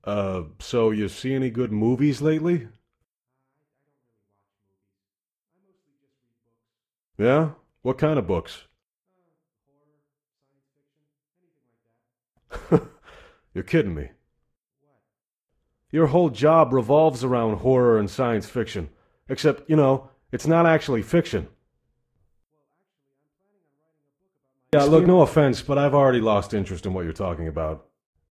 Conversation2a.ogg